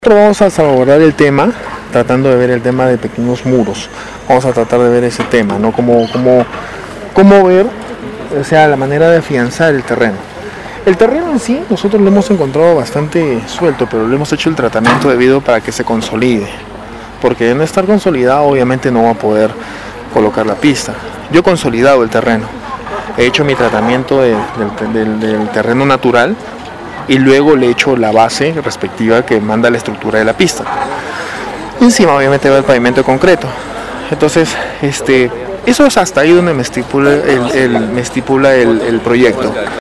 En una entrevista exclusiva para Radio Stereo Villa indicó que no se dejó amedrentar y que ya realizó la denuncia correspondiente, a fin de salvaguardar la integridad de su familia así como la suya.